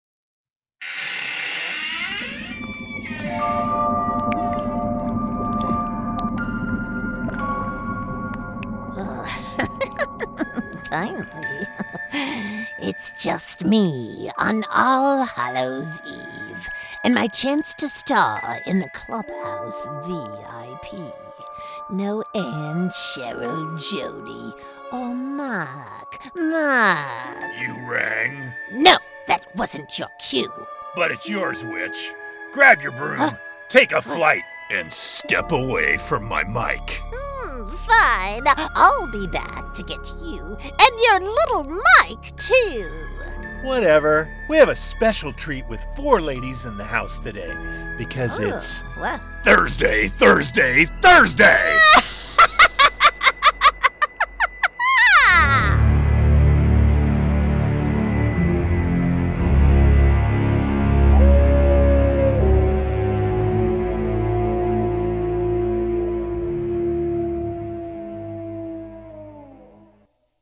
Adult
witch
VIP_Halloween_Intro.mp3